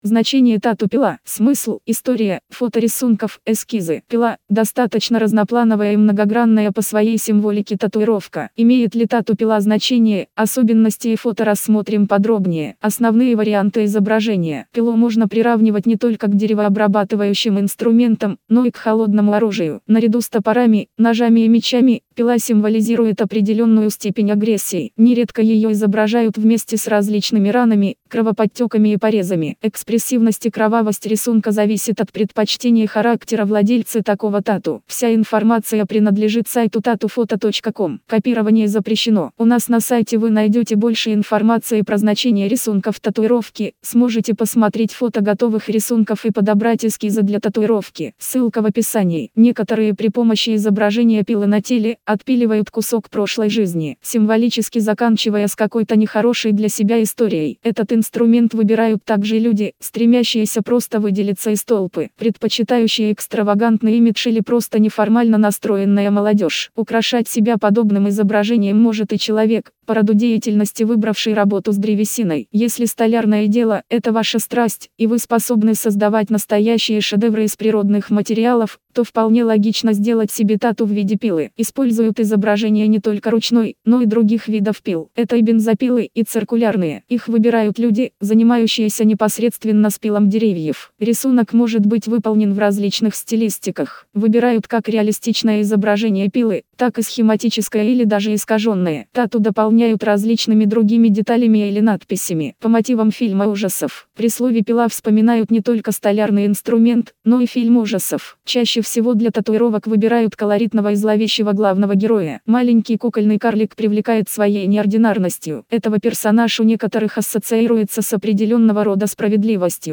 Значение-тату-пила-аудио-версия-статьи-для-сайта-tatufoto.com_.mp3